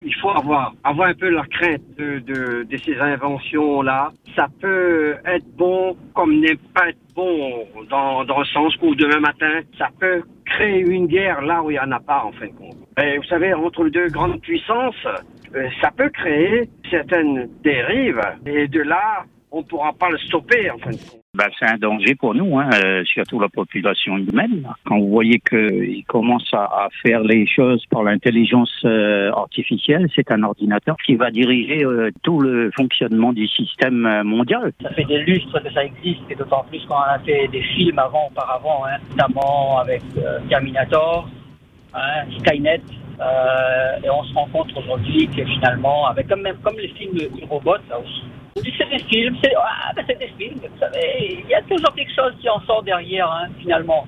Nous sommes allés à la rencontre de plusieurs personnes pour recueillir leur ressenti.